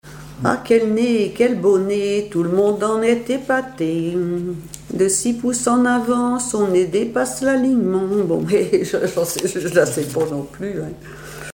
Cantiques, chants paillards et chansons
Pièce musicale inédite